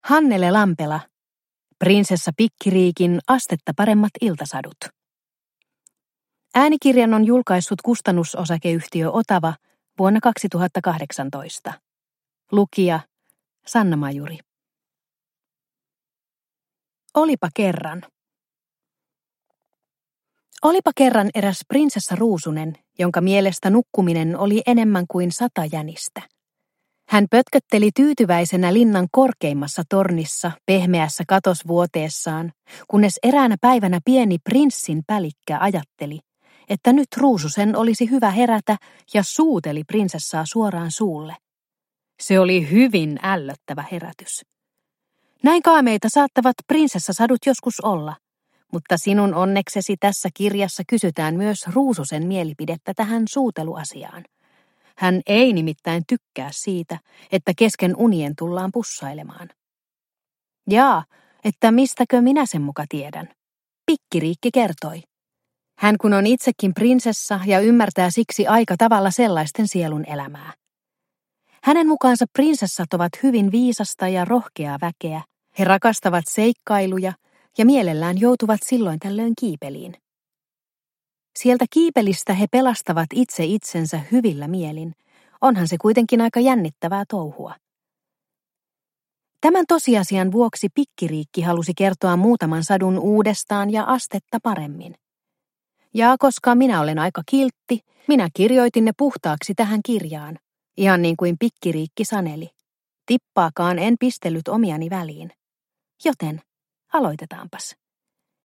Prinsessa Pikkiriikin astetta paremmat iltasadut – Ljudbok – Laddas ner